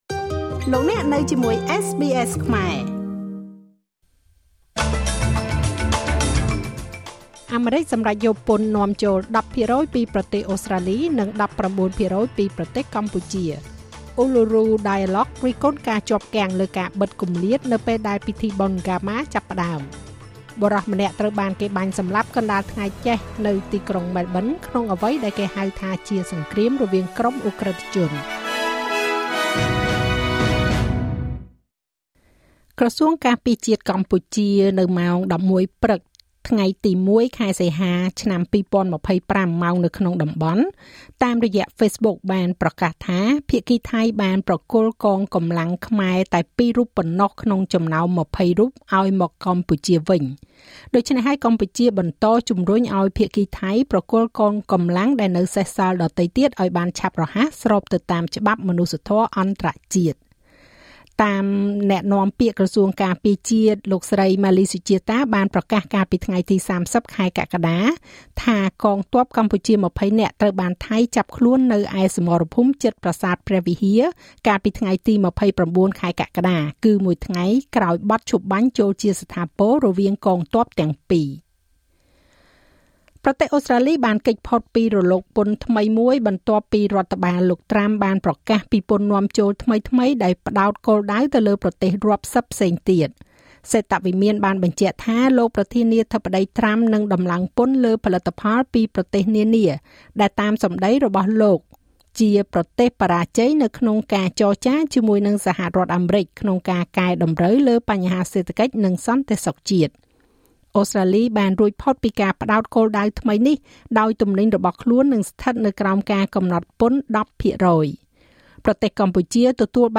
នាទីព័ត៌មានរបស់SBSខ្មែរ សម្រាប់ថ្ងៃសុក្រ ទី១ ខែសីហា ឆ្នាំ២០២៥